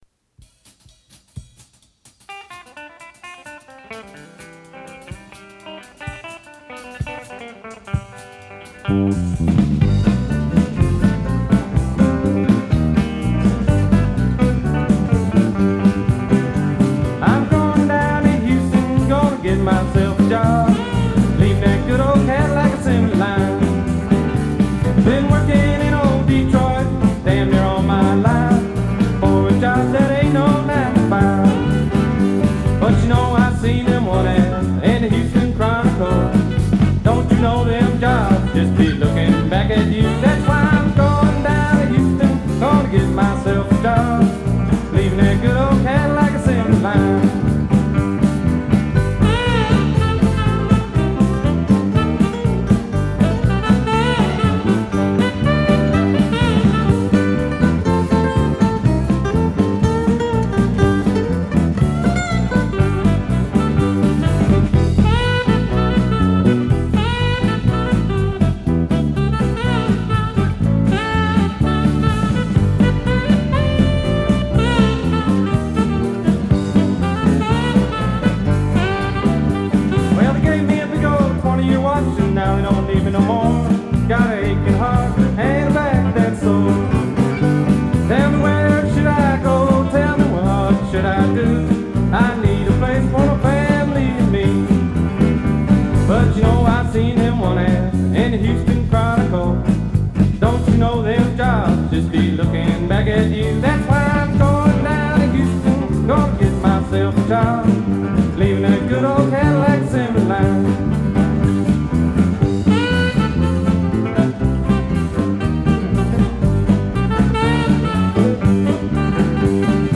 He wrote the songs and was the band’s lead singer and guitar player.